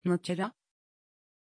Pronunciation of Natyra
pronunciation-natyra-tr.mp3